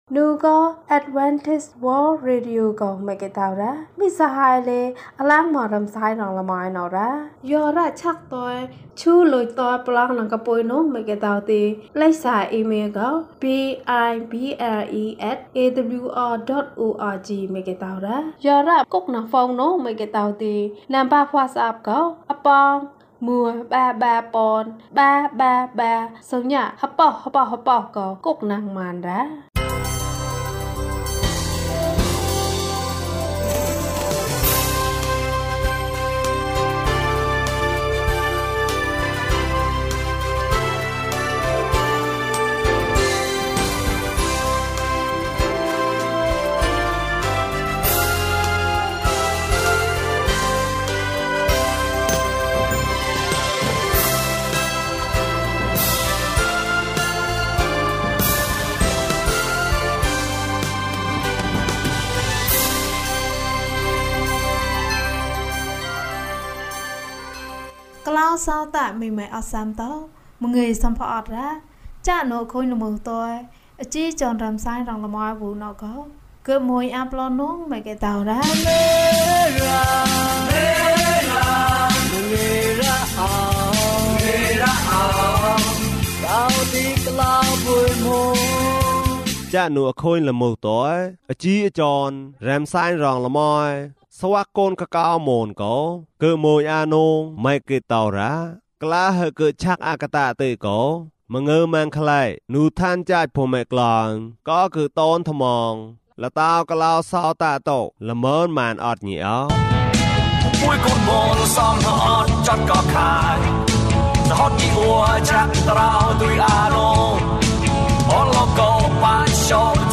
သင့်အသက်တာတွင် ဘုရားသခင်ကို ကိုးစားပါ။၀၂ ကျန်းမာခြင်းအကြောင်းအရာ။ ဓမ္မသီချင်း။ တရားဒေသနာ။